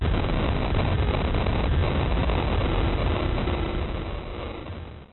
explosion-low.mp3